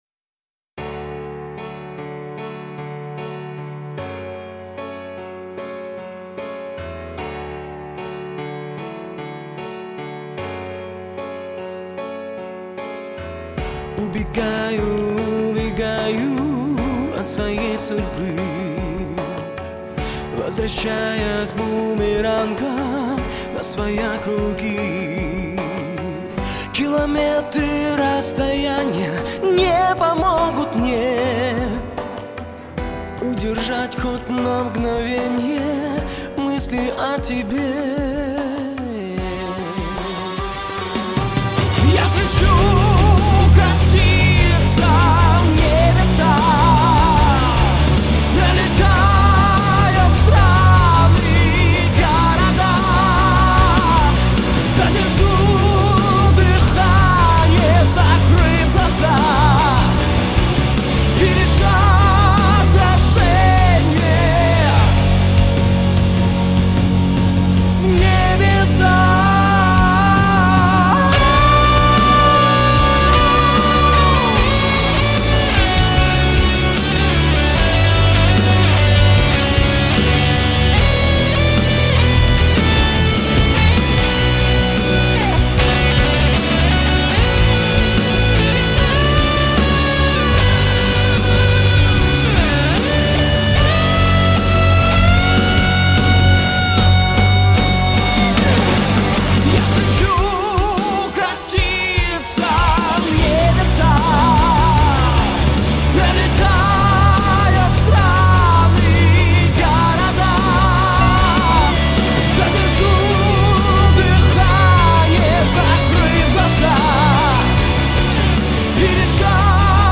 Мужской
Тенор